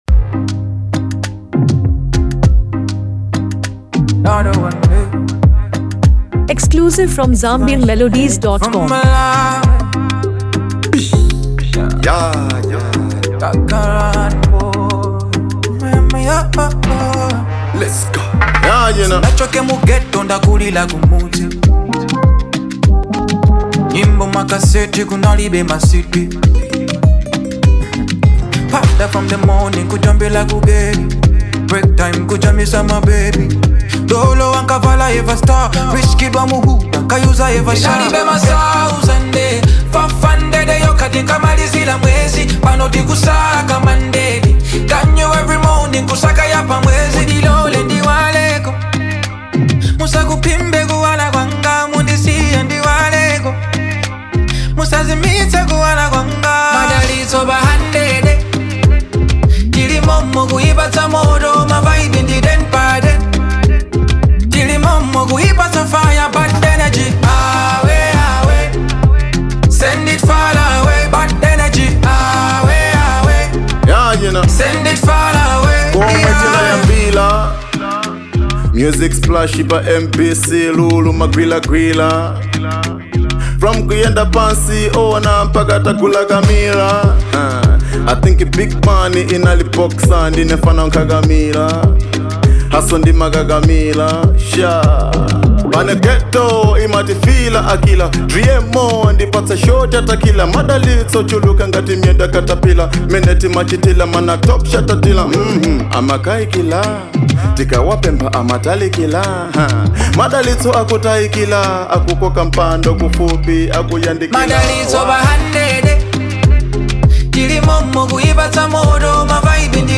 Known for his signature blend of Afrobeat, R&B, and soul
smooth vocals